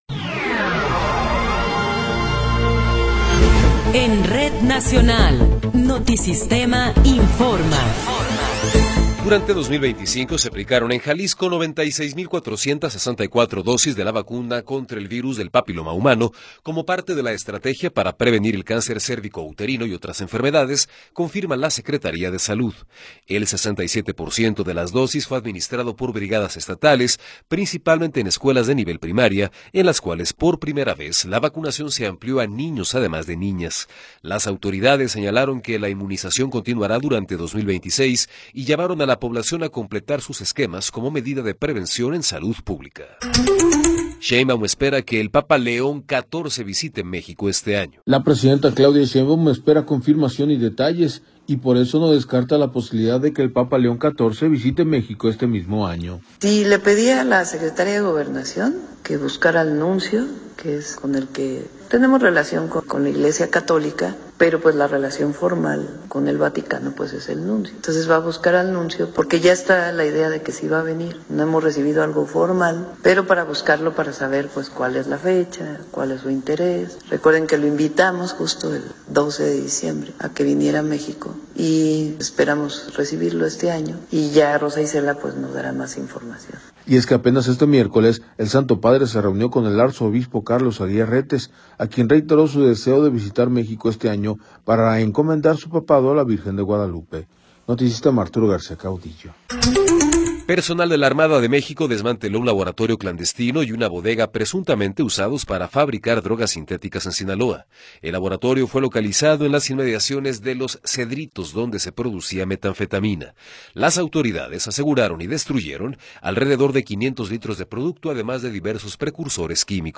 Noticiero 15 hrs. – 24 de Enero de 2026
Resumen informativo Notisistema, la mejor y más completa información cada hora en la hora.